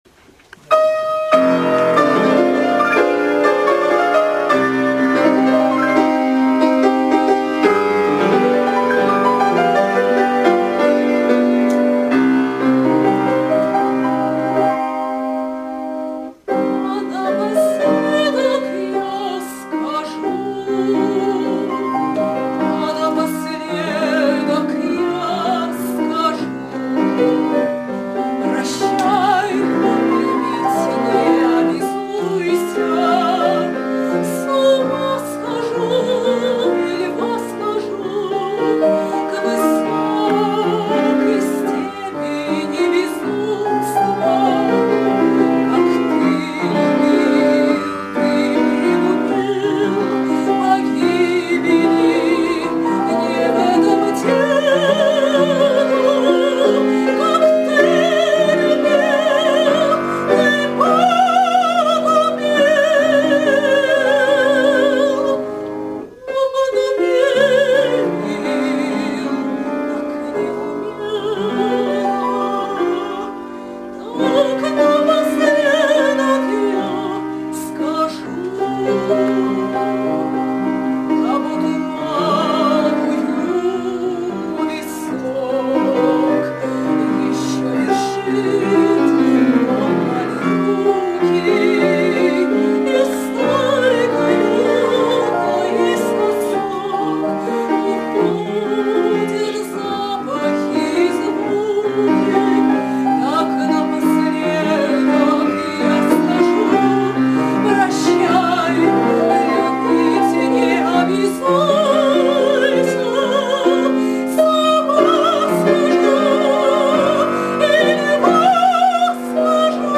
драматическое сопрано